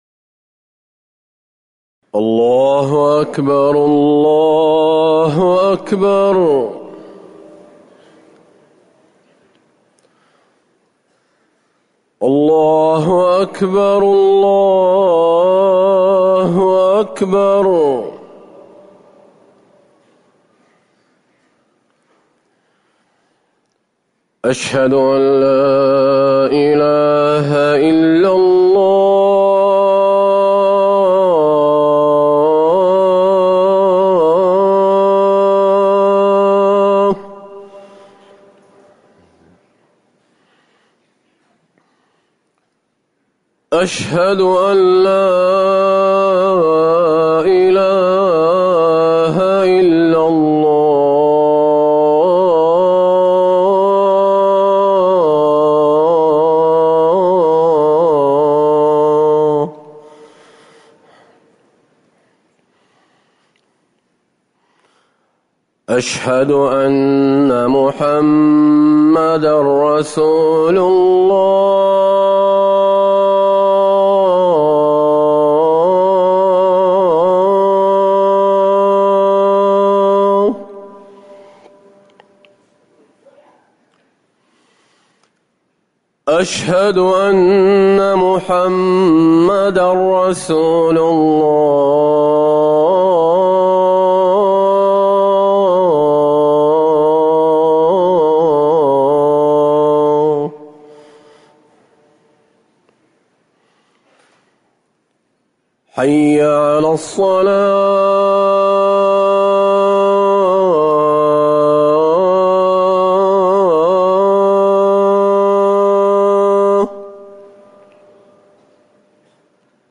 أذان الفجر الثاني - الموقع الرسمي لرئاسة الشؤون الدينية بالمسجد النبوي والمسجد الحرام
تاريخ النشر ١٠ صفر ١٤٤١ هـ المكان: المسجد النبوي الشيخ